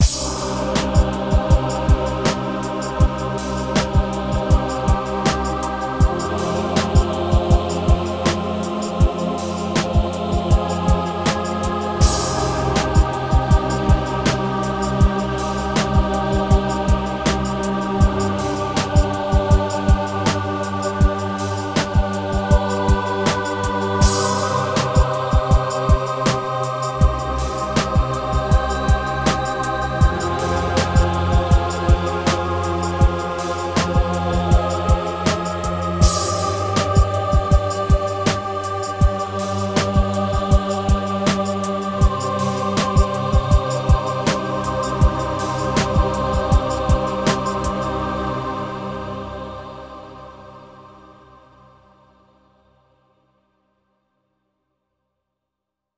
Here's 4 free program sounds powered by choir samples.
free-choir1.mp3